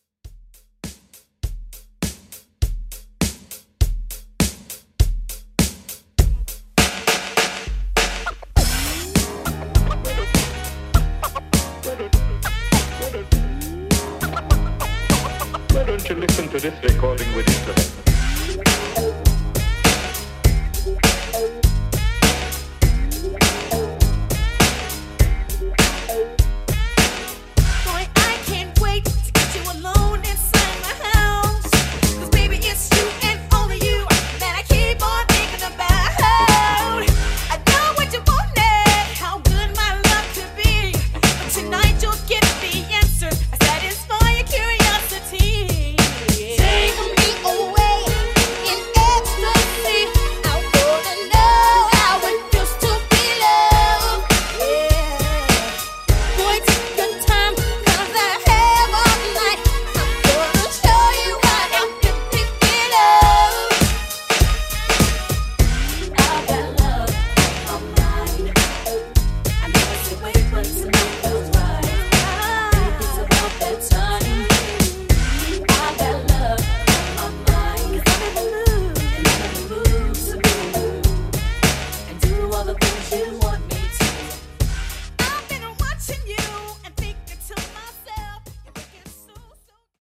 90s R&B Redrum